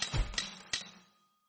BuildTower.mp3